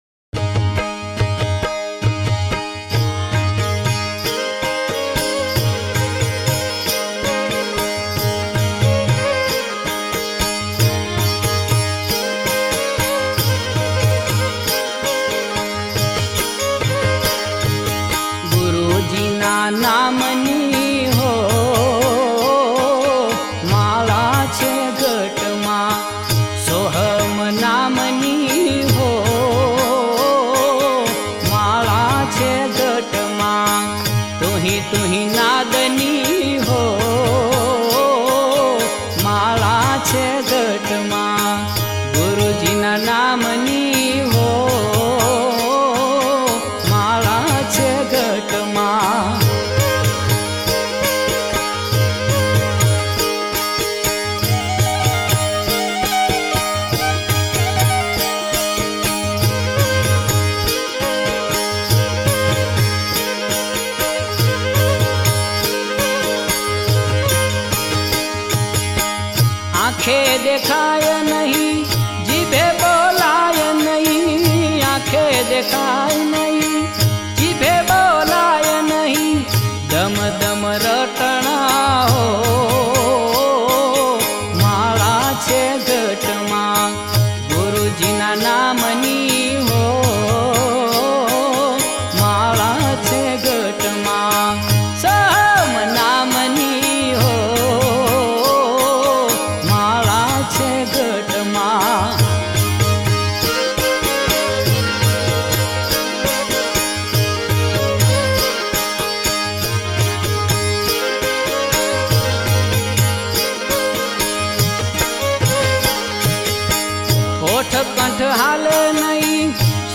ગીત સંગીત ભજનાવલી - Bhajans
Gujarati Bhajan.